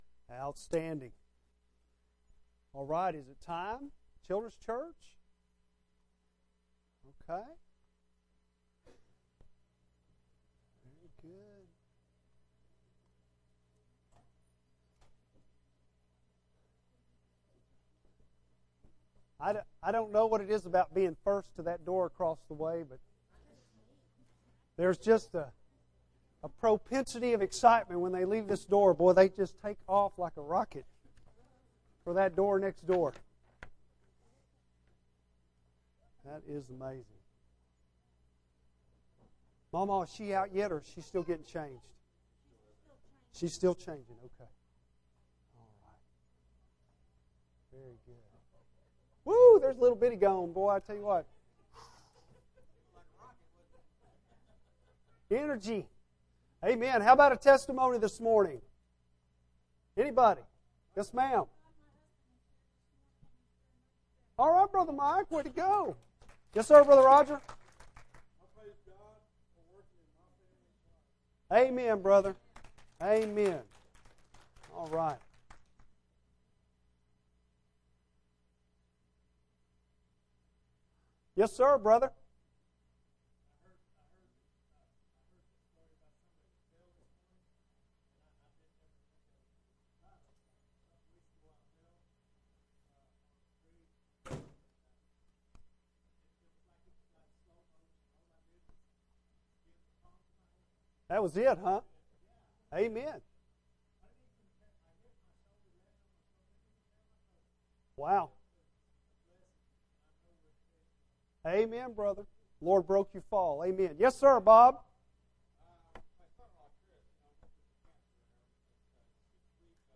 Bible Text: Mark 3:7-19 | Preacher